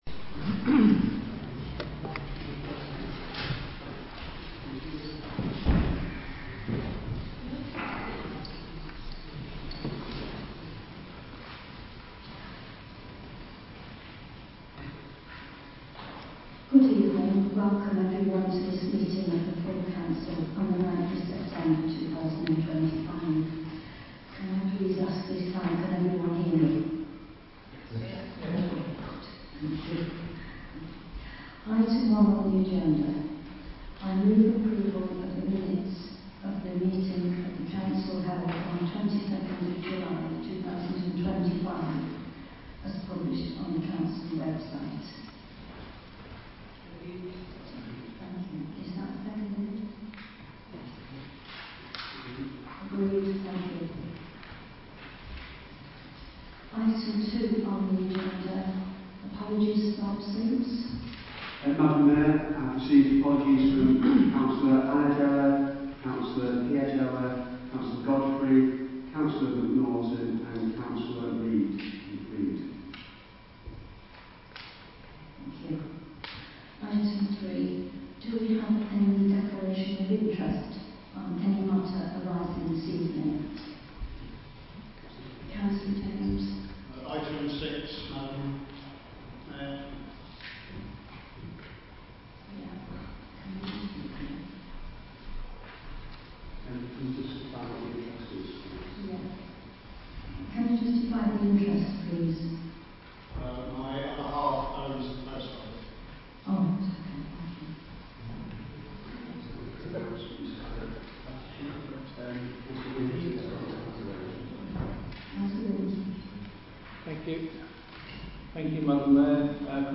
Location: Council Chamber, County Buildings, Martin Street, Stafford